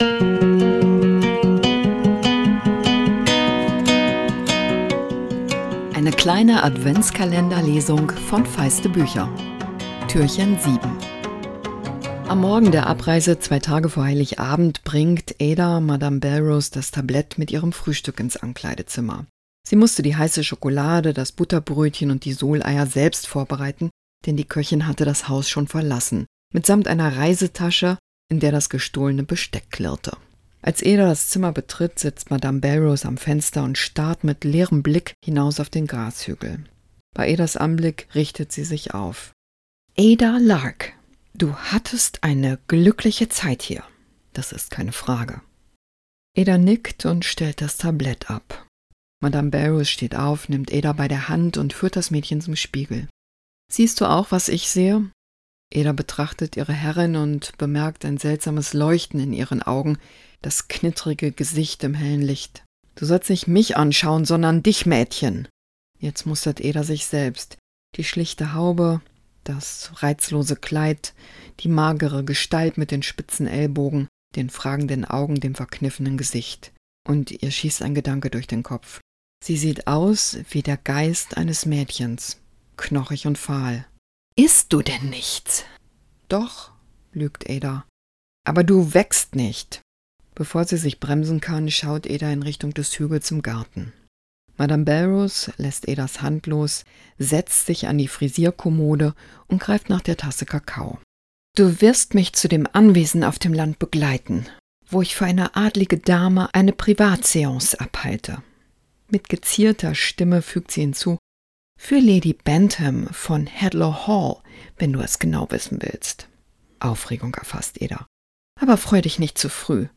Adventskalender-Lesung 2024!